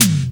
• Eighties Clear Tom Drum Single Hit D# Key 33.wav
Royality free tom one shot tuned to the D# note. Loudest frequency: 4042Hz
eighties-clear-tom-drum-single-hit-d-sharp-key-33-kVD.wav